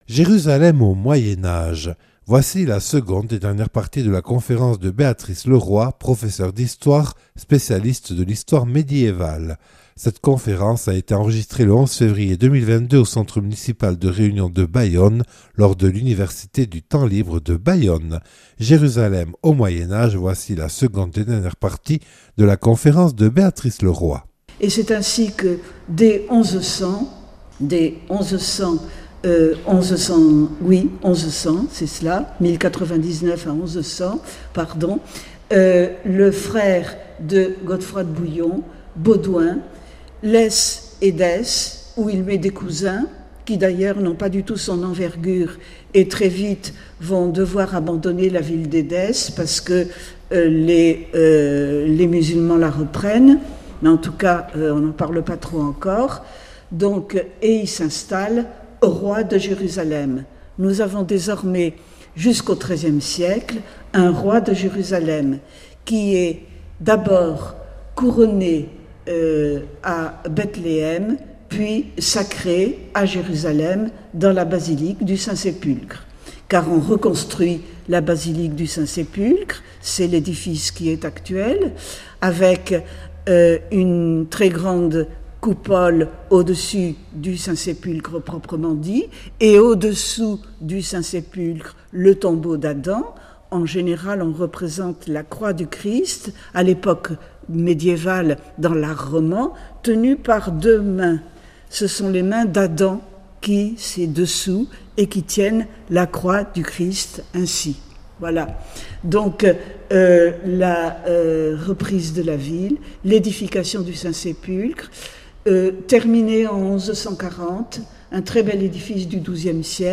(Enregistrée lors de l’Université du Temps Libre de Bayonne le 11 février 2022).